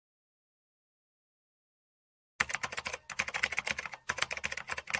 Keyboard Typing
Keyboard Typing is a free foley sound effect available for download in MP3 format.
024_keyboard_typing.mp3